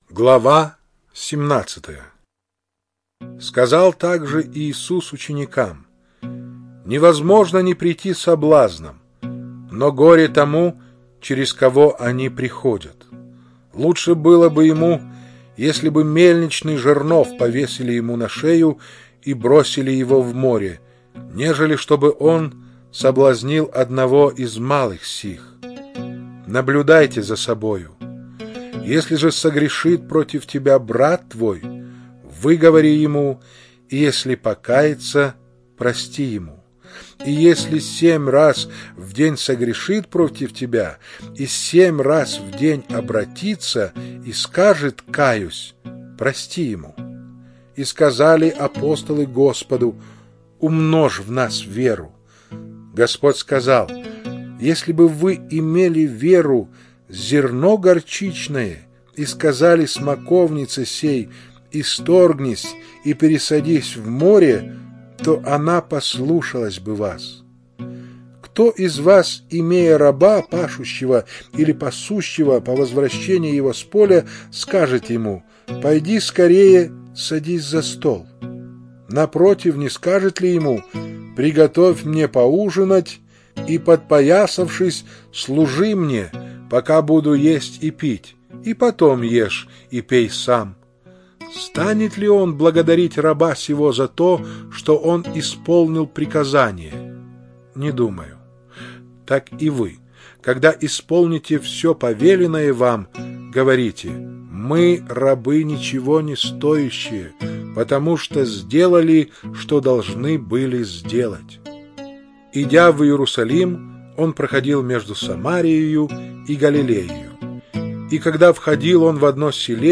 Чтение сопровождается оригинальной музыкой и стерео-эффектами